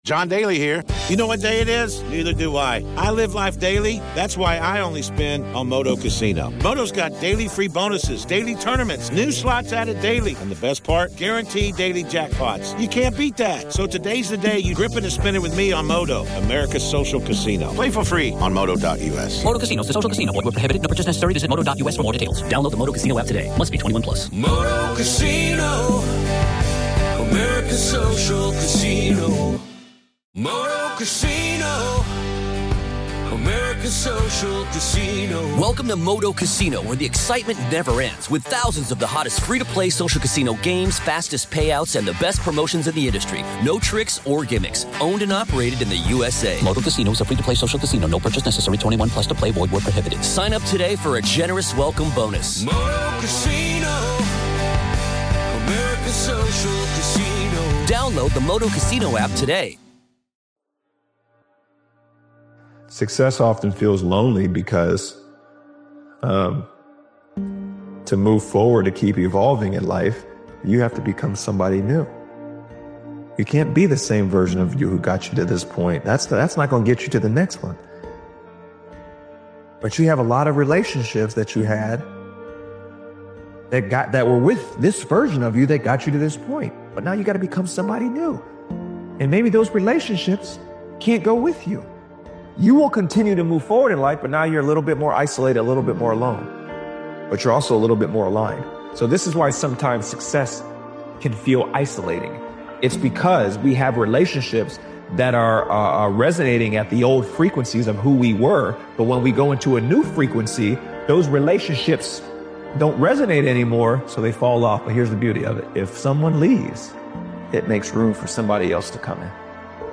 This powerful motivational speech video by Daily Motivations is about stepping away from distractions, cutting the noise, and putting all your energy into becoming who you said you would be.